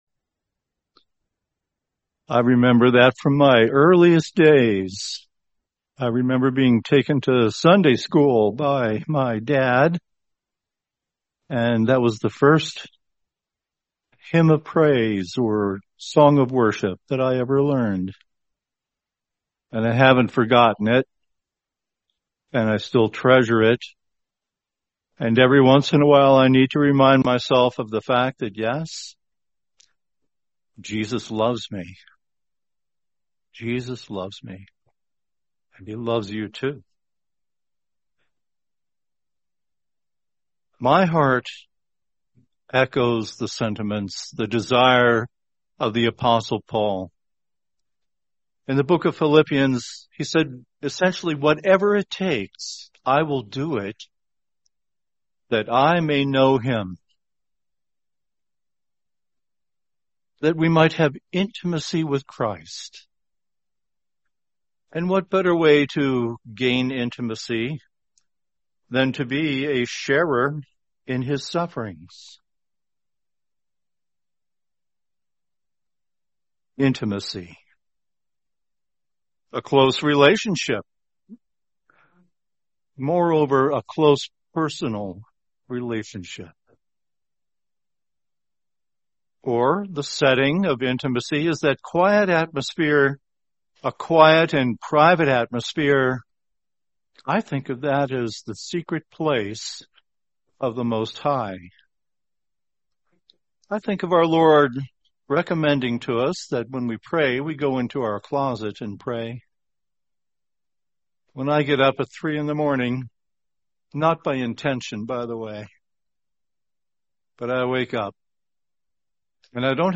Series: 2026 Albuquerque Convention